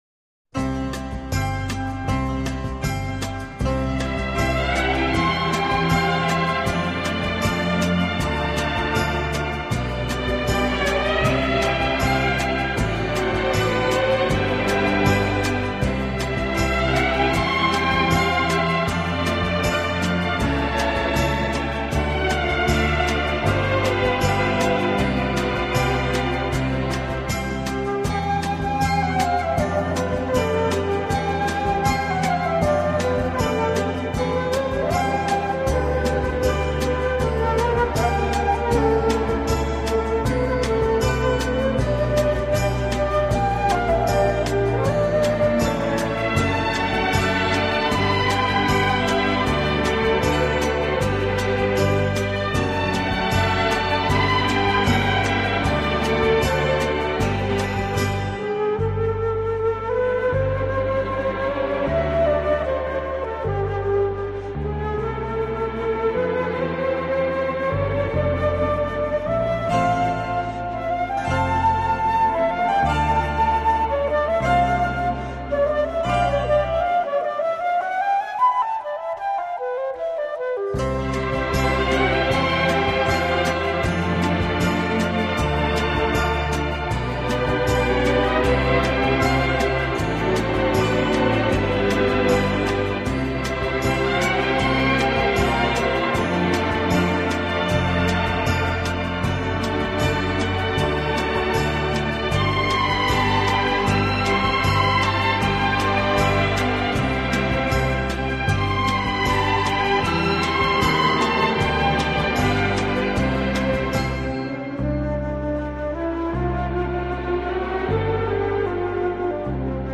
【顶级轻音乐】
世界三大轻音乐团